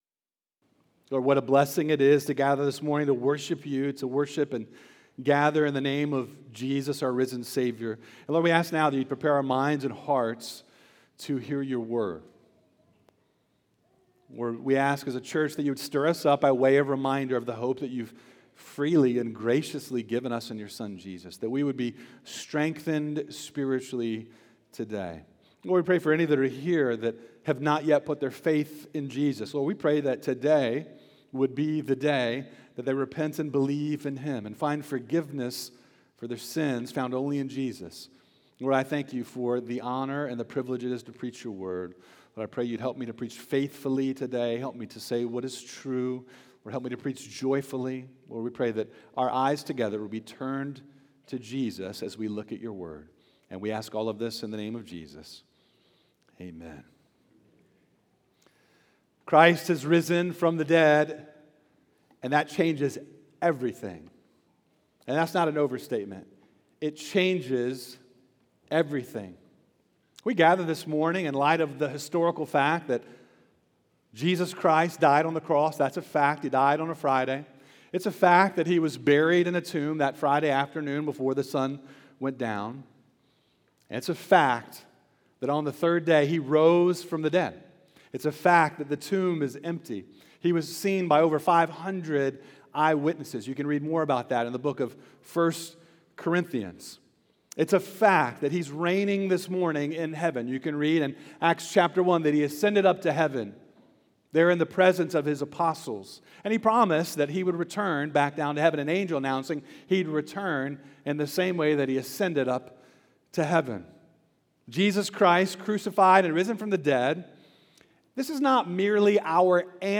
Sermons by Oakhurst Baptist Church Sermons